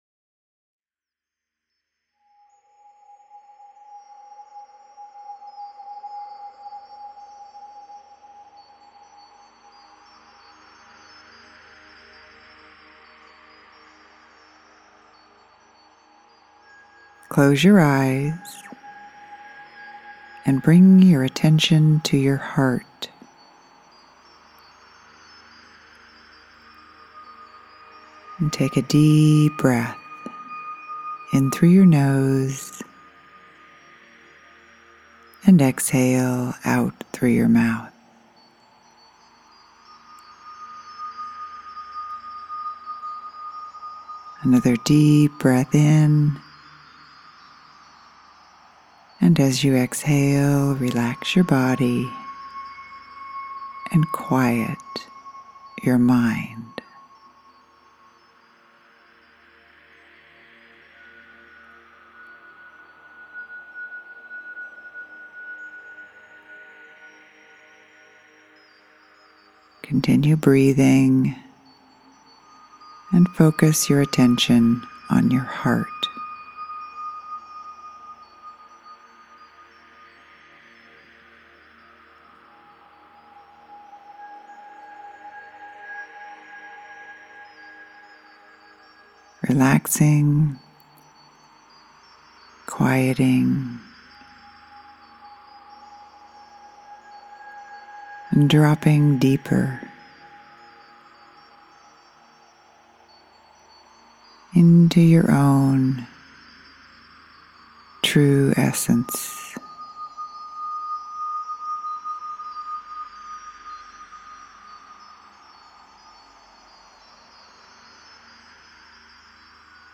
Written and spoken
Music